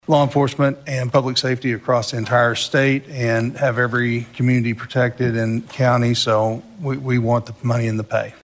CLICK HERE to listen to commentary from House Budget Chairman, Kevin Wallace.